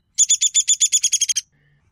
This is a beginner-level comparison group of rattle-like bird songs and sounds from Land Birds of North America, Eastern/Central region.
Downy Woodpecker - Rattle call
High-pitched and distinctly falling; Downy goes down.